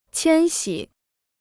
迁徙 (qiān xǐ): migrer; se déplacer.